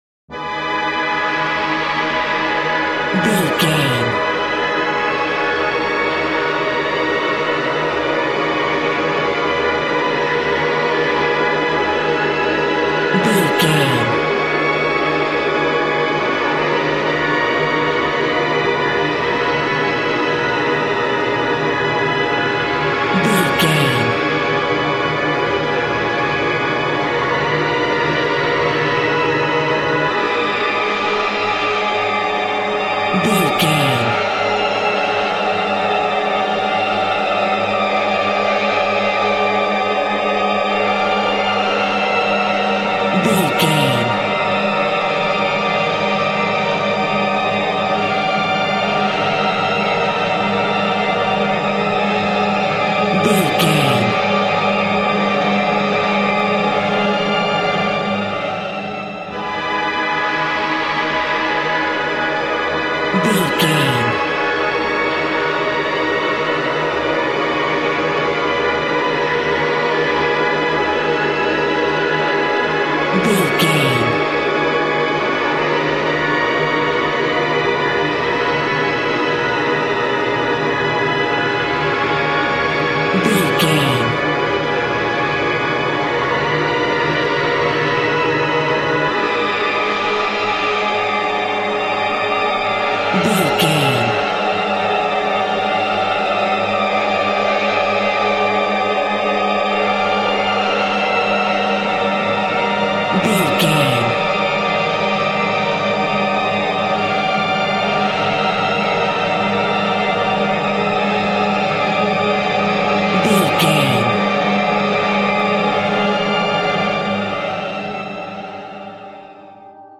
In-crescendo
Aeolian/Minor
Slow
ominous
suspense
eerie
Horror synth
Horror Ambience
electronics
synthesizer